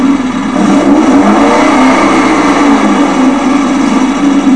(Although I think the exhaust is still a bit
noisy).
daddycar.wav